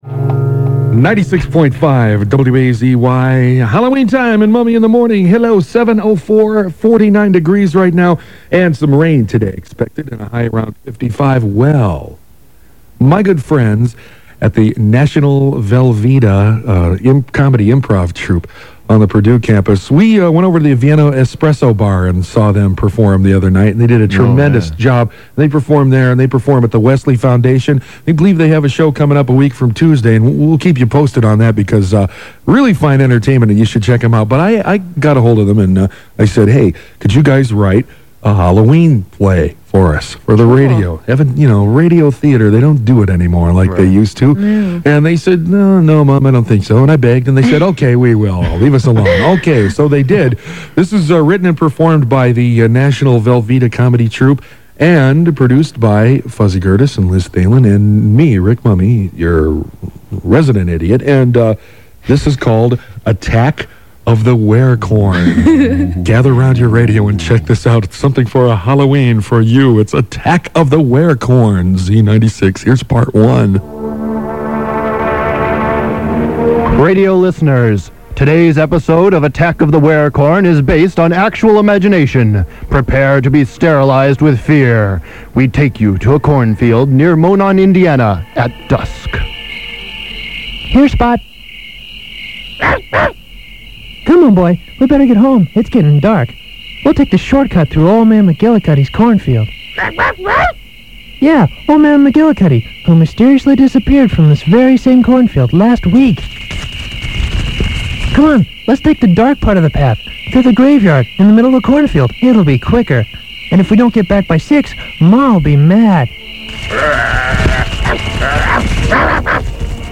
Of a National Velveeta sketch we did on the radio in 1994.